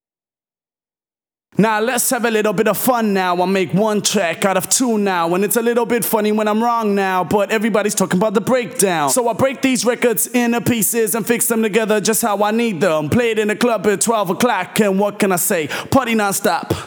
VTDS2 Song Kit 128 BPM Rap 1 Out Of 2
VTDS2 Song Kit 02 Rap 1 Out Of 2 Vocals WET.wav